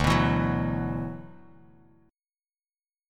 Listen to D6add9 strummed